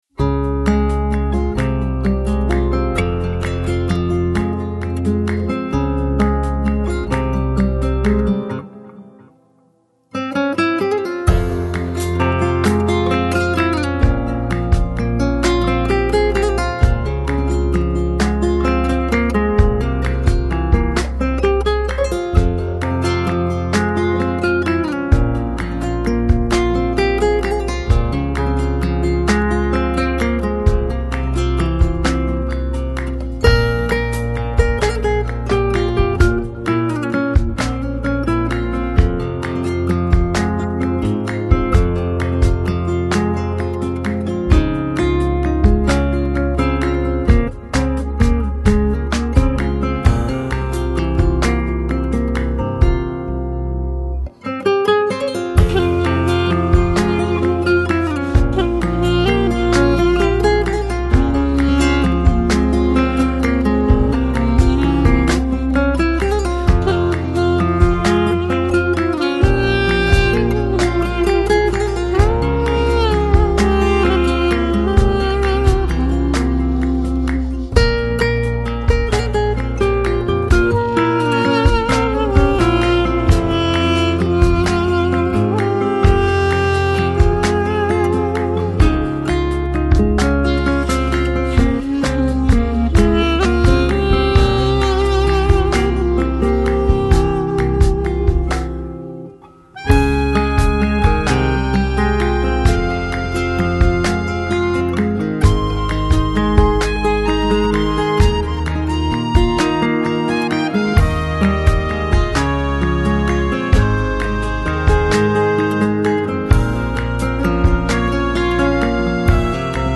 Жанр: Instrumental, Flamenco, World